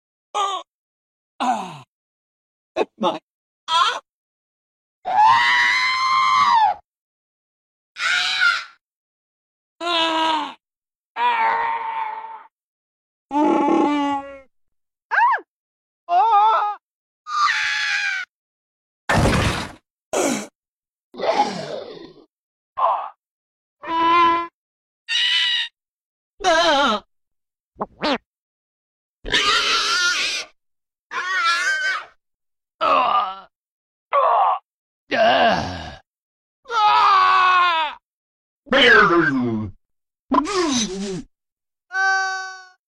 Lego Star Wars Unalive Noises Sound Effects Free Download